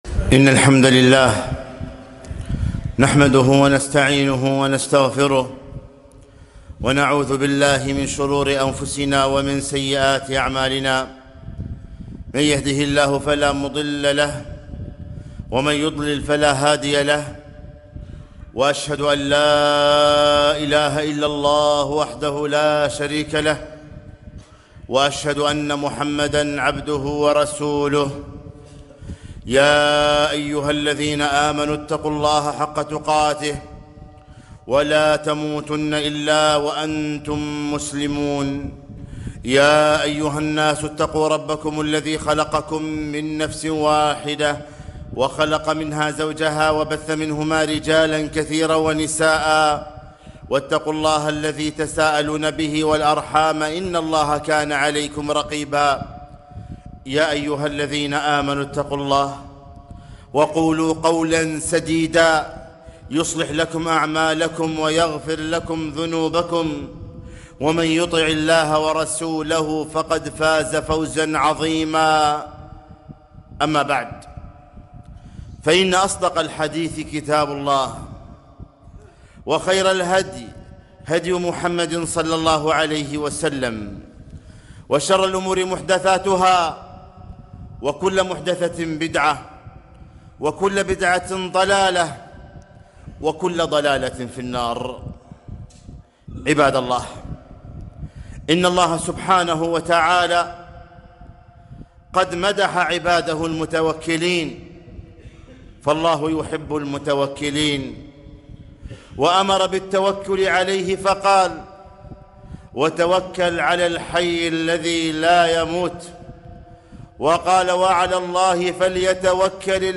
خطبة - احذر التشاؤم